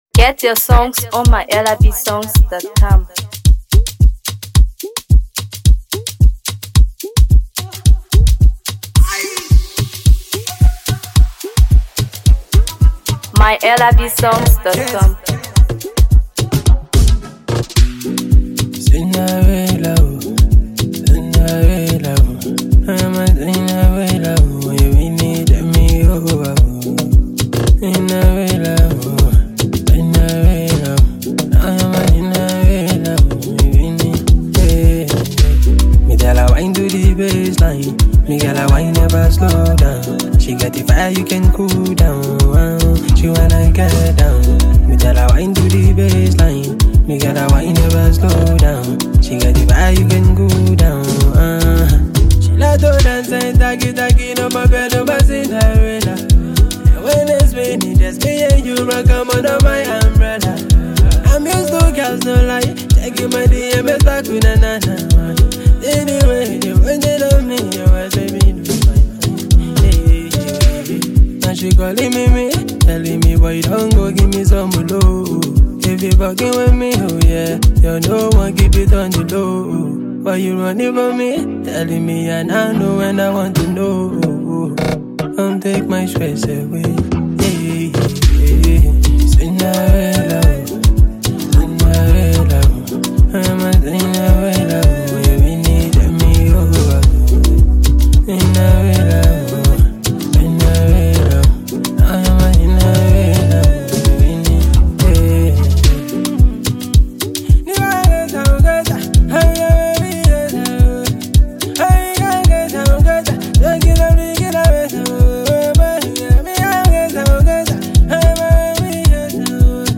Afro PopMusic